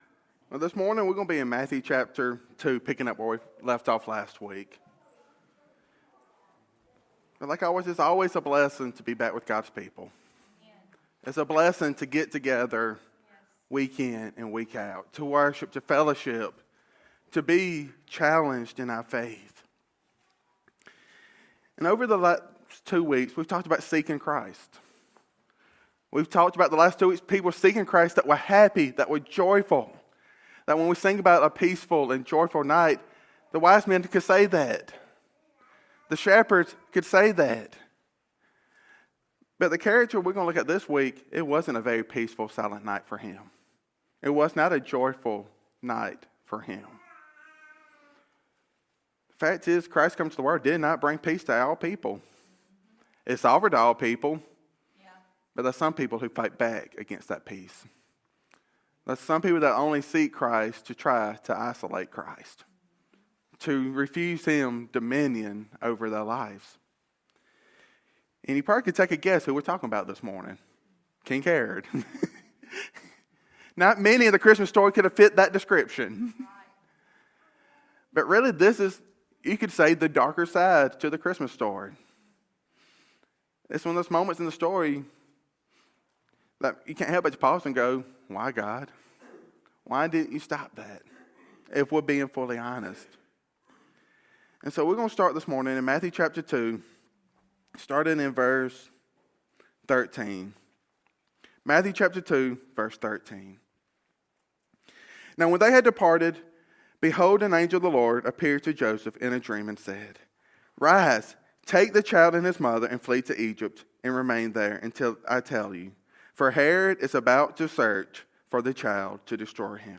In this sermon, we look at the life and reaction of King Herod—a man who sought Christ not to worship Him, but to destroy Him.